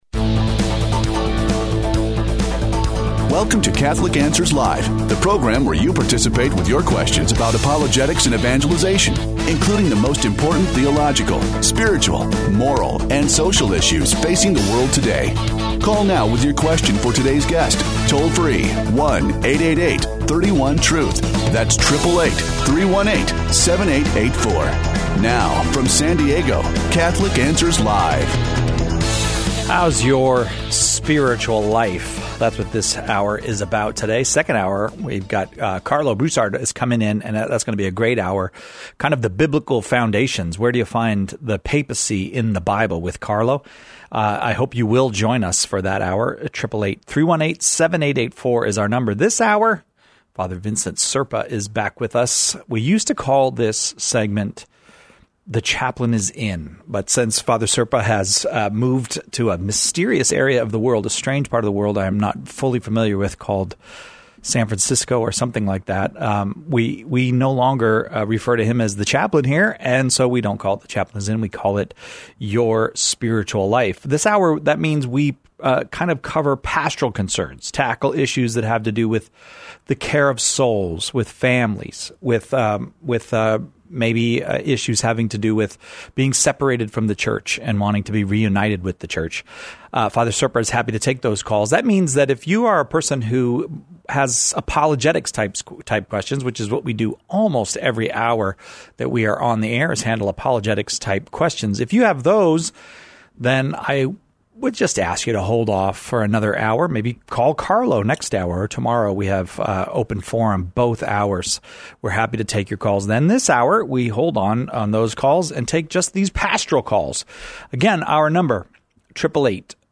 takes questions of a pastoral nature in this hour devoted to the care of souls, growth in the spiritual life, and healthy relationships.